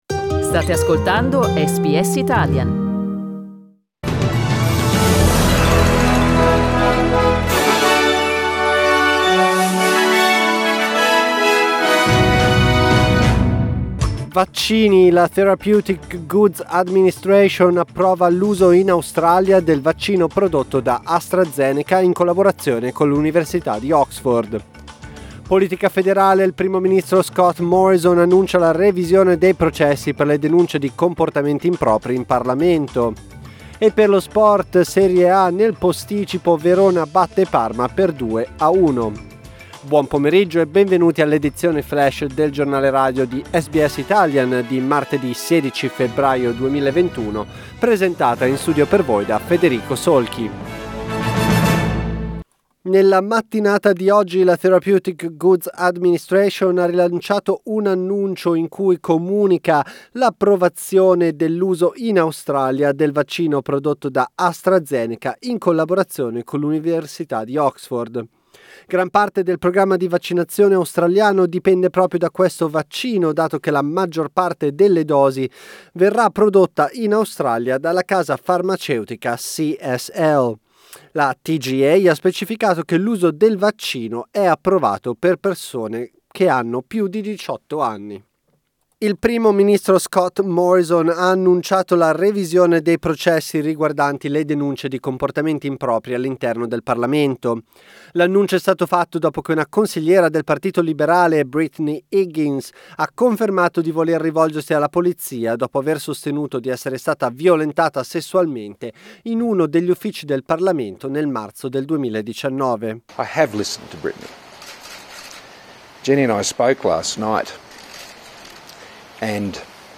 Our news update in Italian